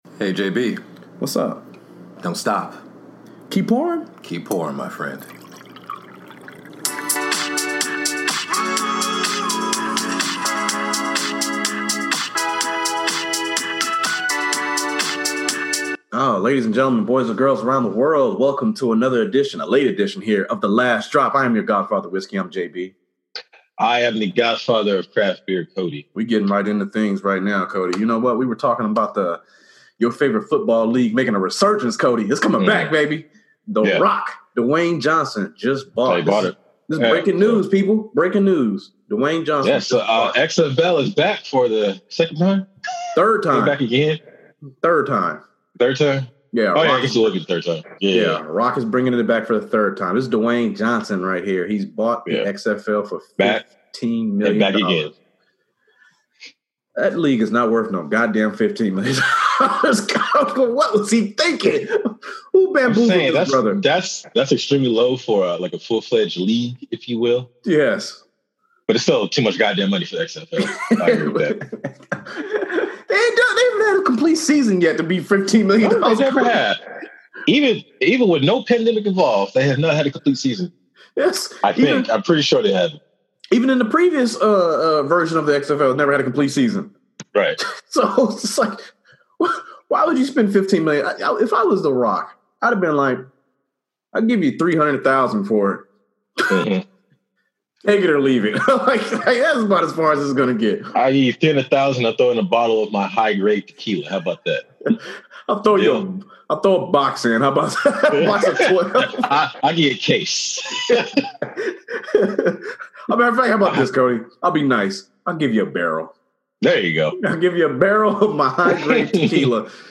It's whiskey week & no better way to kick things off with a store pick of Weller Full Proof from Red Hill Liquor. Also for being late, I kept in a little off the recording skit of bs'ing at the end of the show.